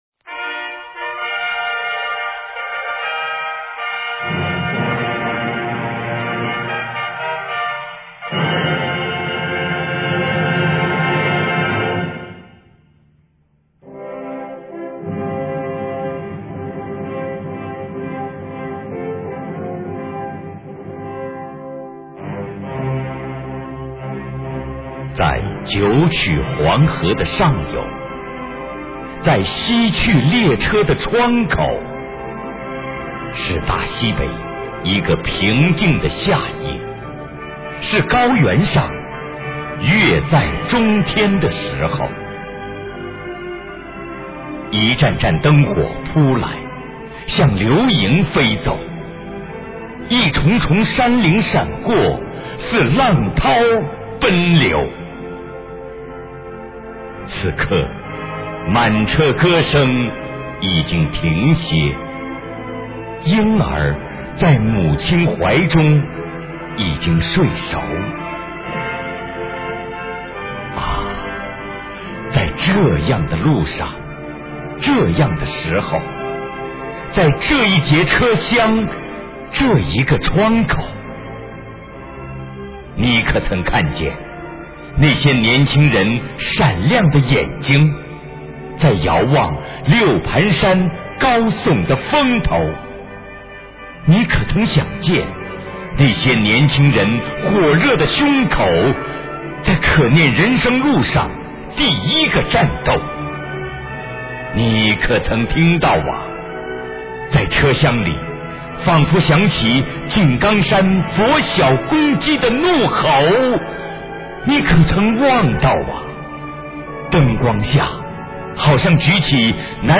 又一版本的配乐诗朗诵《西去列车的窗口》，这是较早 的录音，声情并茂、以情帯声，感人至深的程度无以言表！